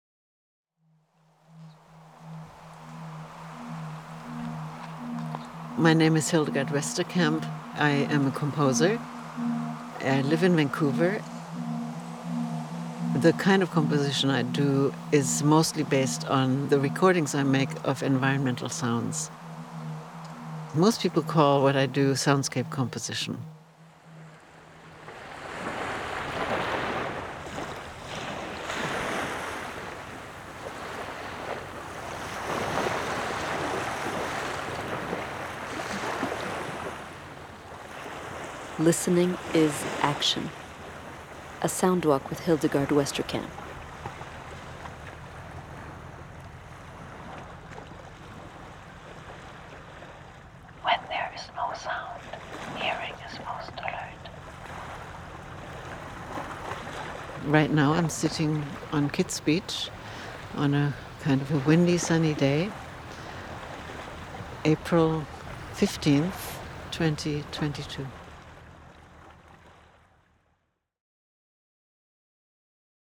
Radio documentary EN
She talks us through her first field recordings, the idea of soundwalks, her work at the Vancouver Co-operative Radio and her participation in the World Soundscape Project. She takes us to listen to the places she recorded more than twenty years ago, the sounds of which constitute the material for some of her soundscape compositions.